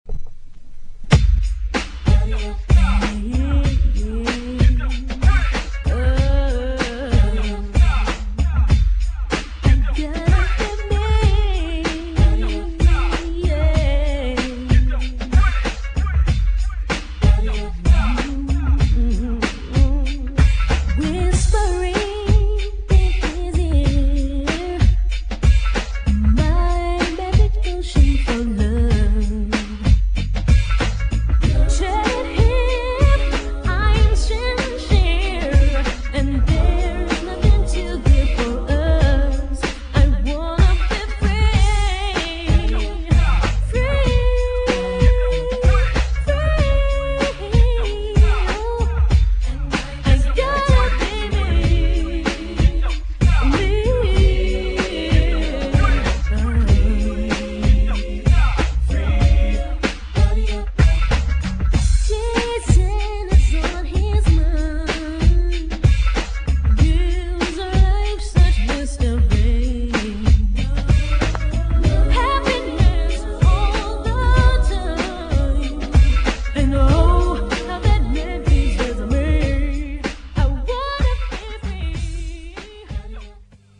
HIP HOPフレーバーをふんだんに採り入れたナイスな90's R&B!!
REMIXとオリジナル収録。
GENRE R&B
BPM 91〜95BPM